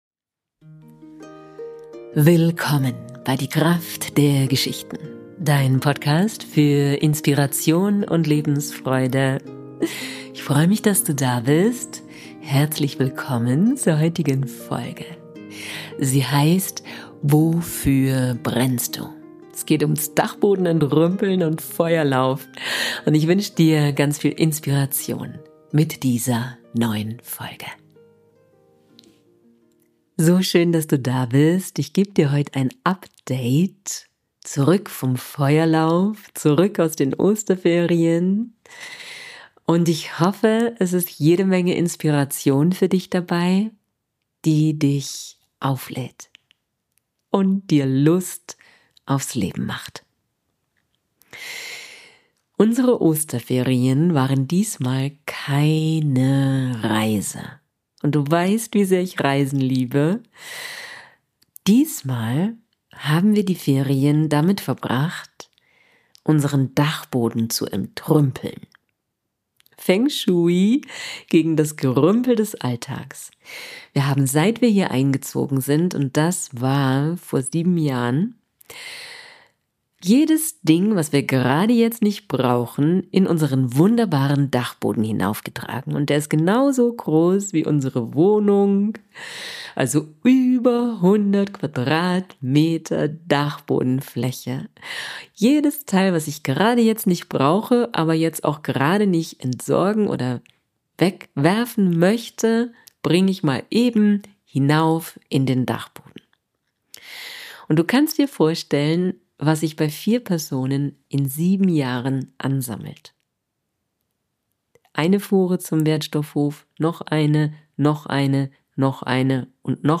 Heute gibt es eine Prise Feng Shui gegen das Gerümpel des Alltags. Und eine kraftvolle Meditation für Dich.